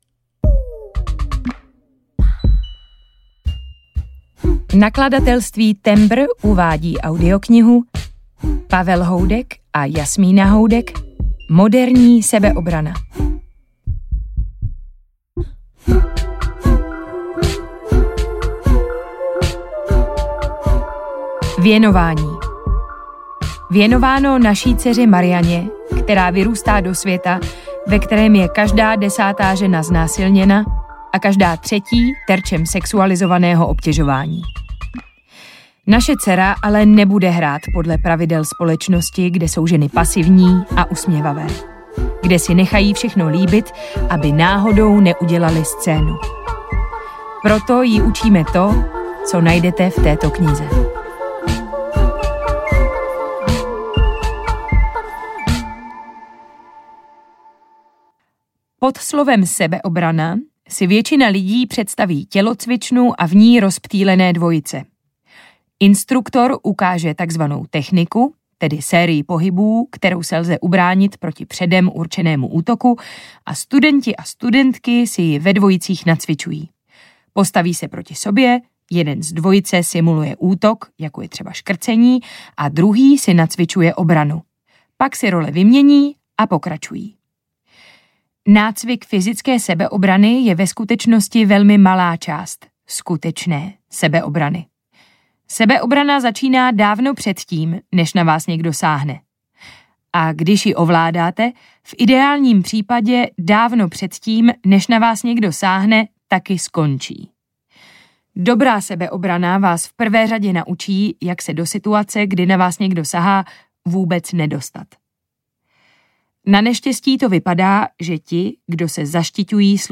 Moderní sebeobrana audiokniha
Ukázka z knihy